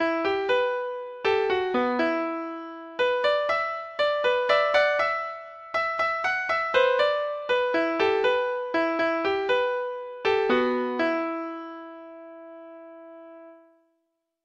Folk Songs from 'Digital Tradition' Letter ( (unknown title)